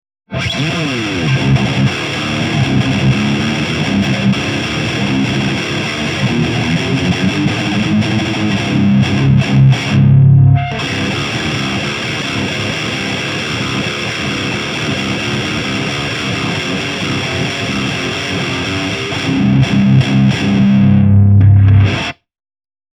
サンプルはブースターとしての違い程度です。
JCM2000 DSL100
GAIN7 Bass8 Middle8 Treble7
LDDE2 ZW-44 WYLDE OVERDRIVE